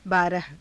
ふつう